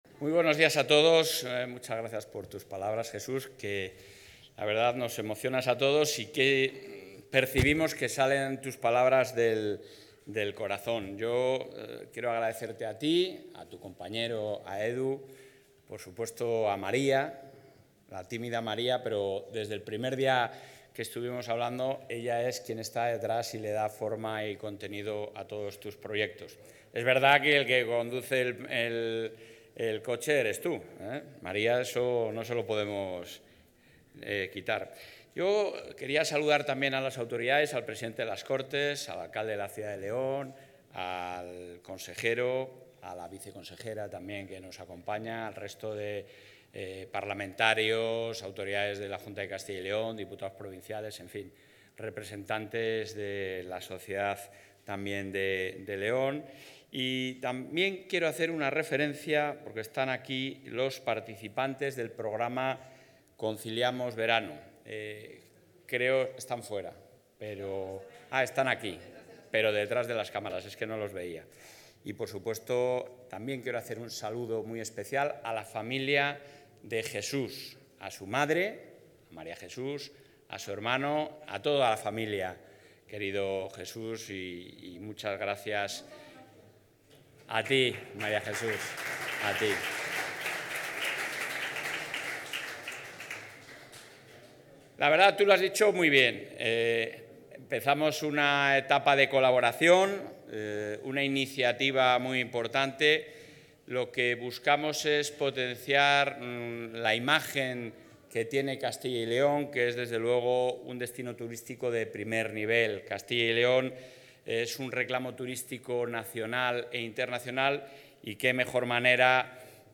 Intervención del presidente de la Junta.
El presidente de la Junta de Castilla y León, Alfonso Fernández Mañueco, ha participado hoy en León en el acto de presentación del coche y del equipamiento con el que el leonés Jesús Calleja competirá en la 'Baja España Aragón 2025' y en el 'Rally Dakar 2026', y en las que llevará los logotipos 'Turismo Castilla y León' y 'Castilla y León nos impulsa', con el objetivo de proyectar la imagen de la Comunidad como un atractivo turístico de primer nivel.